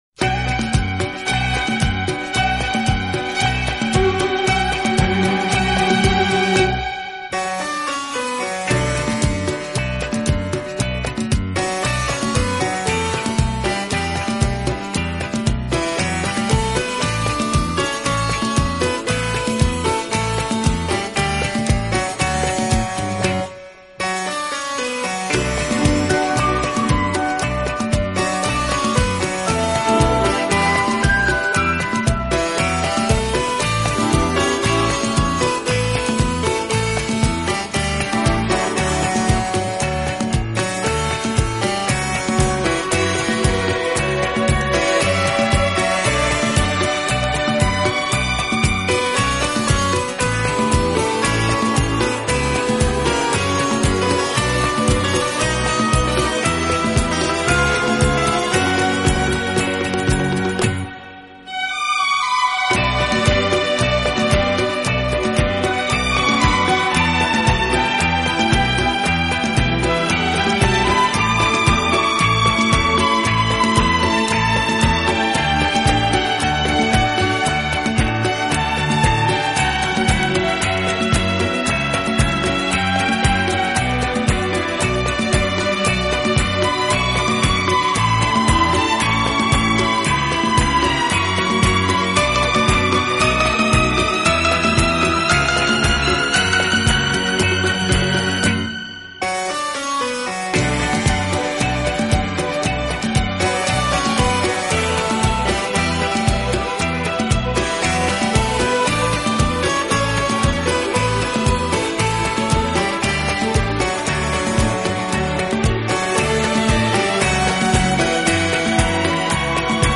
音乐类型：Instrumental, Easy Listening
好处的管乐组合，给人以美不胜收之感。